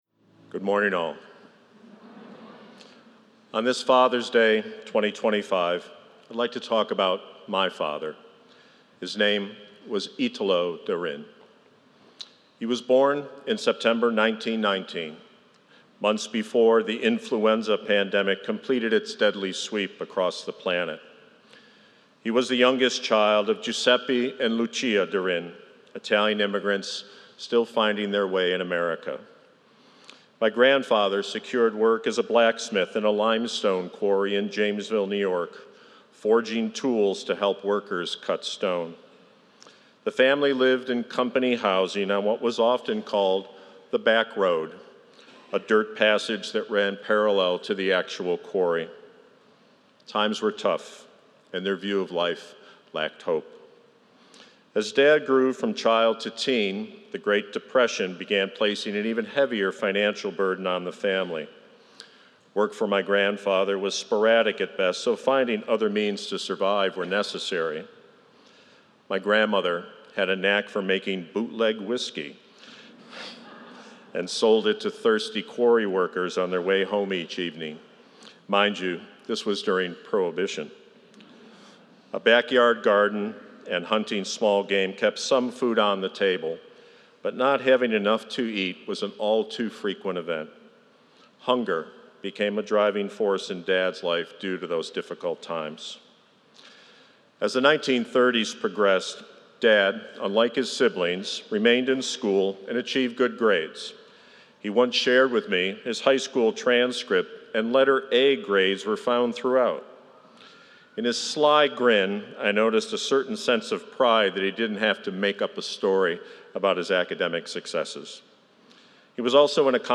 Fathers Day Liturgy 2025